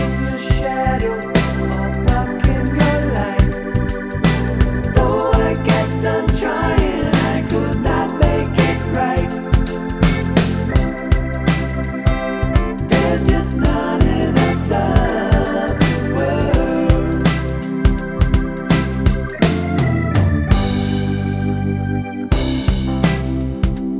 No Guits Rock 3:45 Buy £1.50